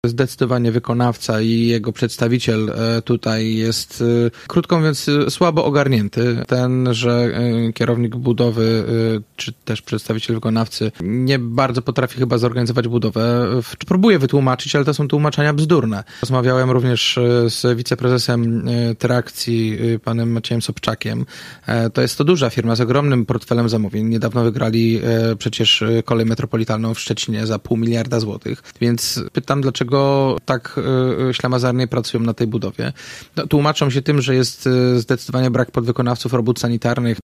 To, że remont nie przebiega tak, jak powinien, przyznał w naszym studiu prezydent Gorzowa.